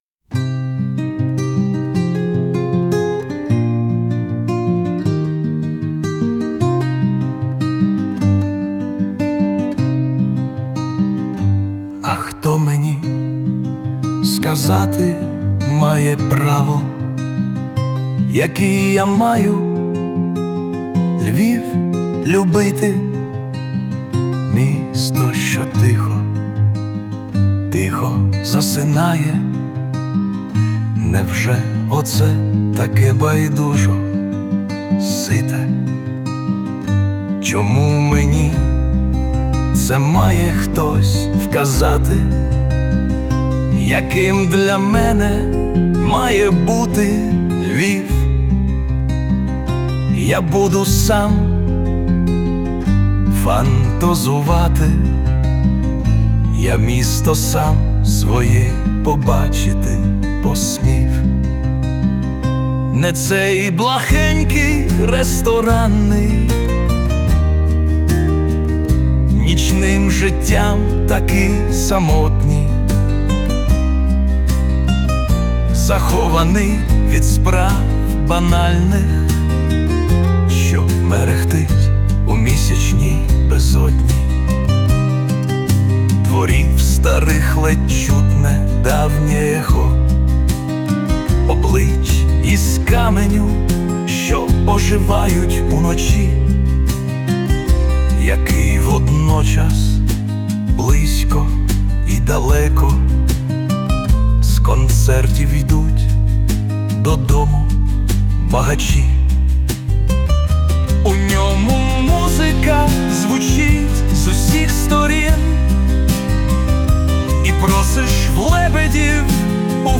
Присутня допомога SUNO
СТИЛЬОВІ ЖАНРИ: Ліричний
Гарна пісня про гарне місто! 16